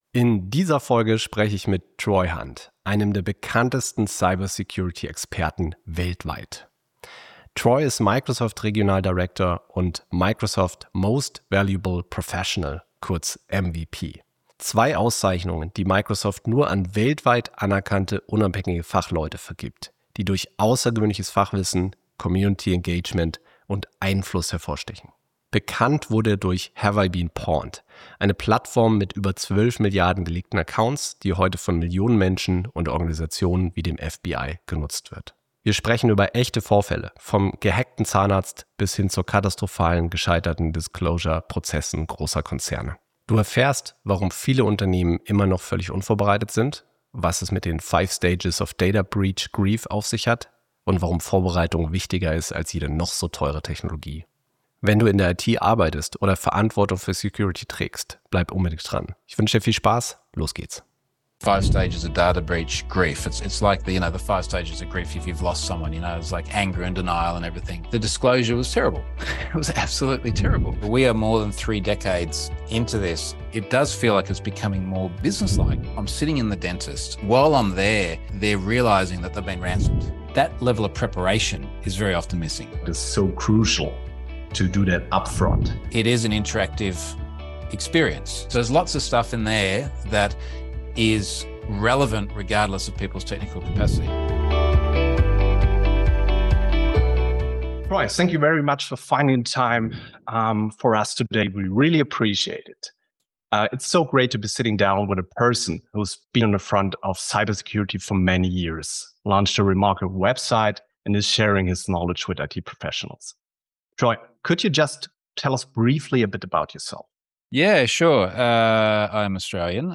In dieser Episode spreche ich mit Troy Hunt, dem Kopf hinter Have I Been Pwned – einer Plattform mit über 12 Milliarden kompromittierten Accounts, die heute von Millionen Menschen und Organisationen...